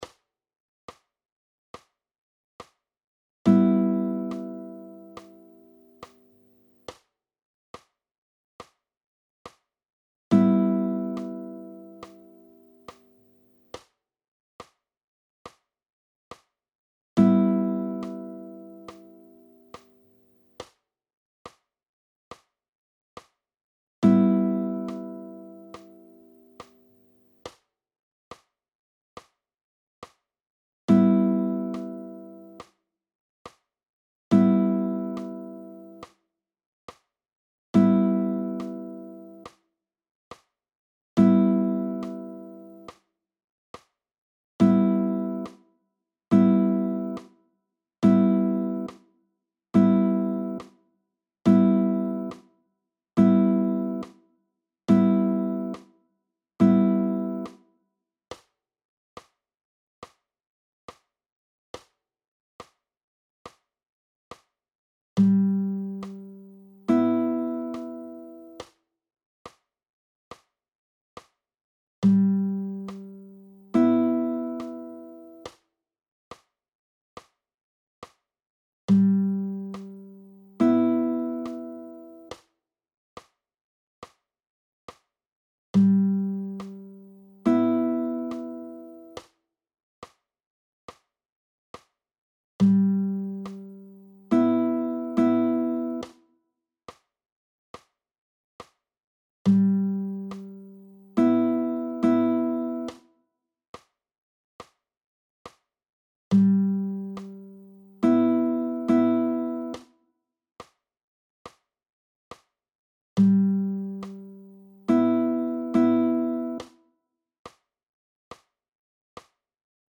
I.1) freier Anschlag mit p, i, m: PDF
Audio, 70 bpm: